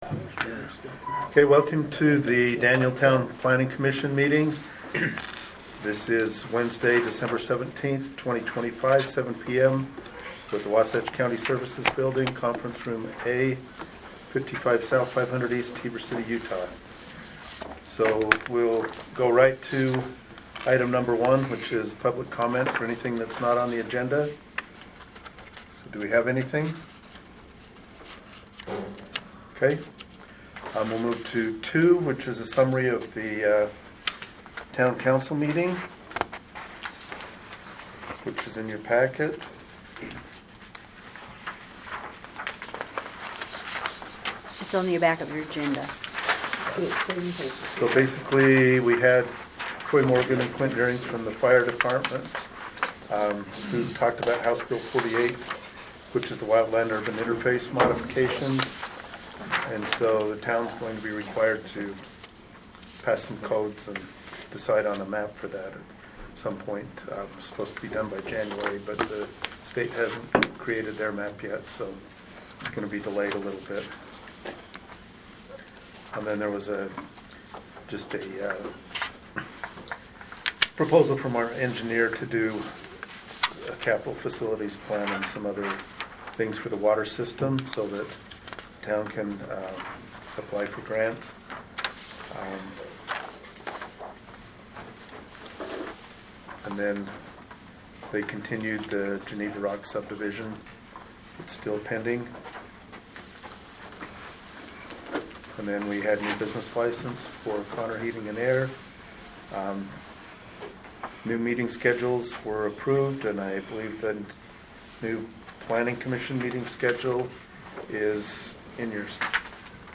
December 17, 2025 Planning Commission Audio Minutes